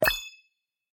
ding.ogg